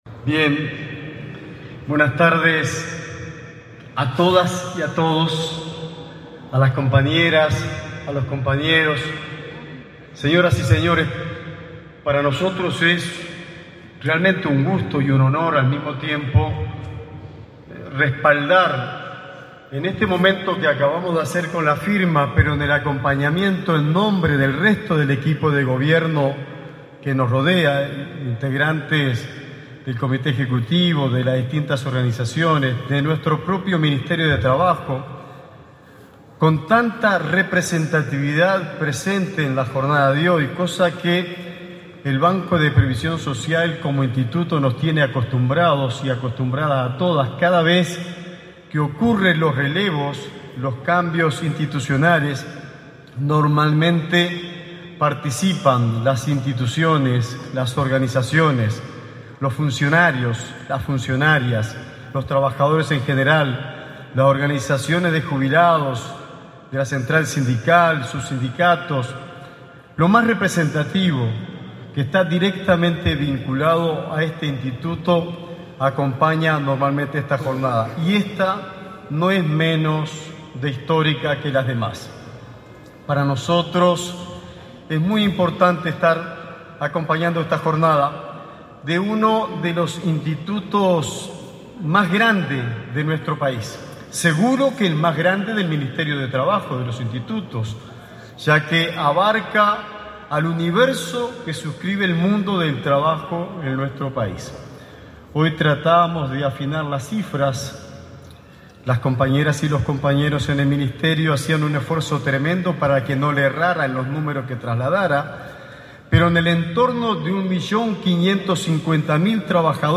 Palabras del ministro de Trabajo y Seguridad Social, Juan Castillo
El ministro de Trabajo y Seguridad Social, Juan Castillo, participó, este 27 de marzo, en la asunción de autoridades del Banco de Previsión Social.